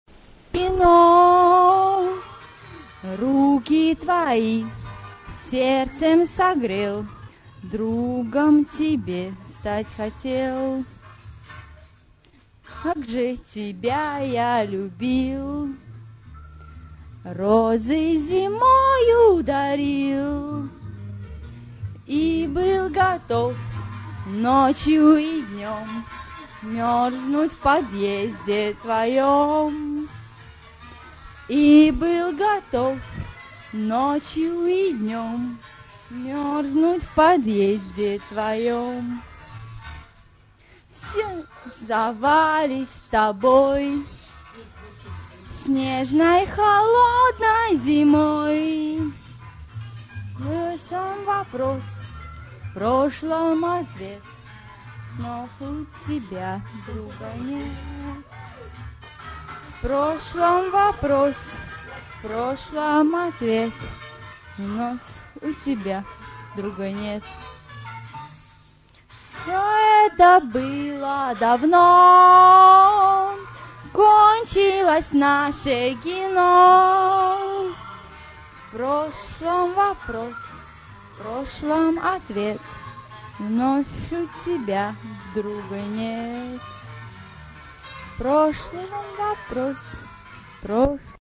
Поёт фанатка